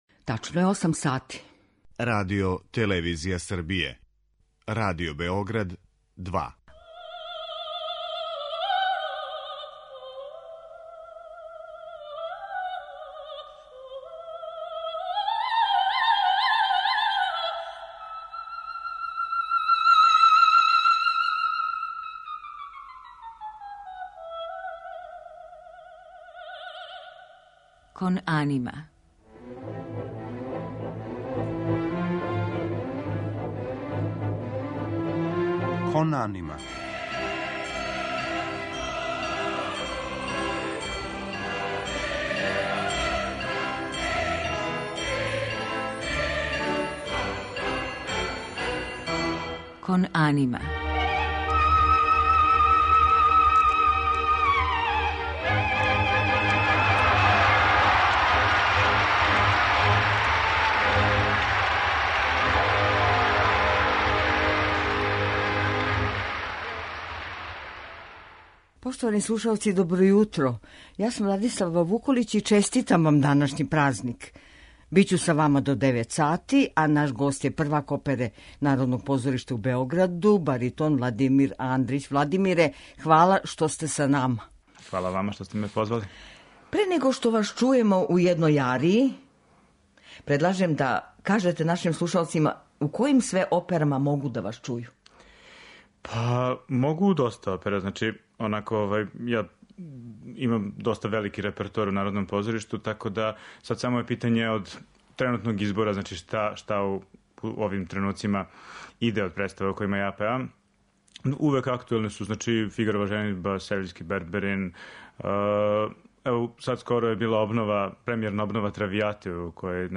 Говориће о својим улогама као и о наградама које је освојио на међународним такмичењима вокалних уметника. У музичком делу емисије биће емитоване арије из опера Волфганга Амадеуса Моцарта, Гаетана Доницетиа, Ђузепа Вердија и Шарла Гуноа у његовом тумачењу.